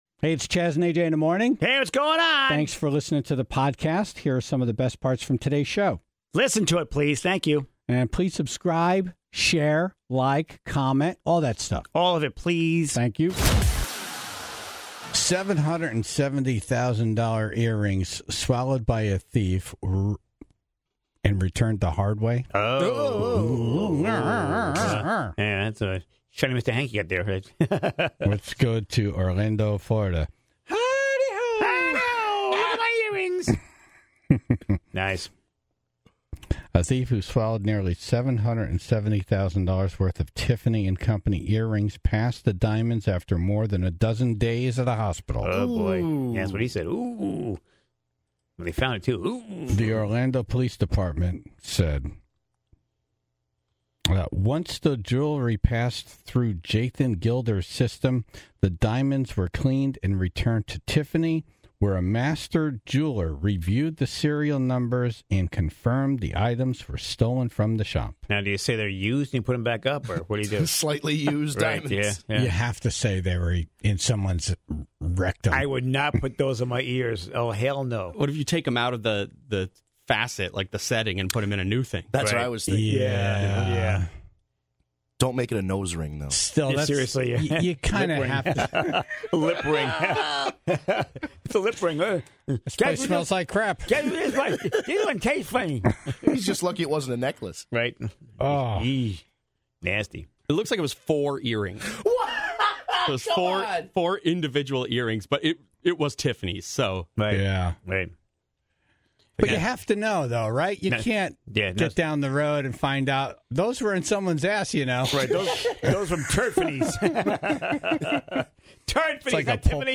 The Tribe calls in to share more stories of items they have swallowed.